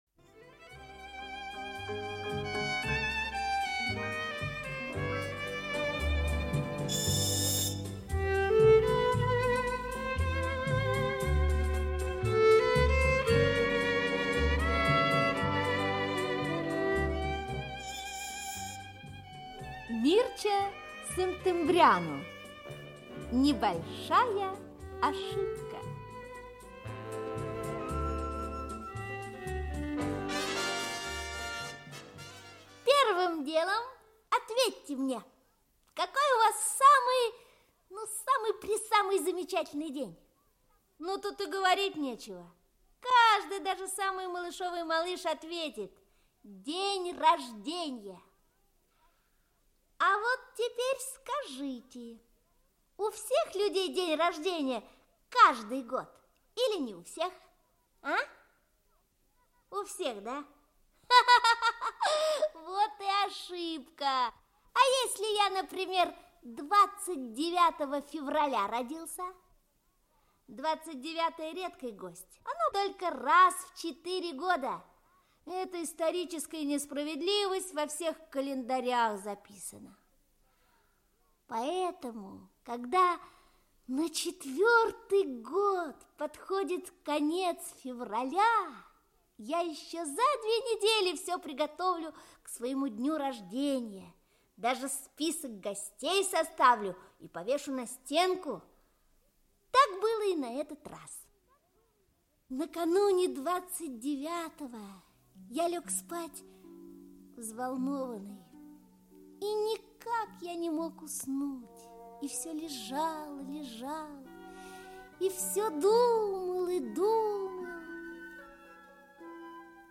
Аудиокнига Небольшая ошибка | Библиотека аудиокниг
Aудиокнига Небольшая ошибка Автор Мирча Сынтимбряну Читает аудиокнигу Актерский коллектив.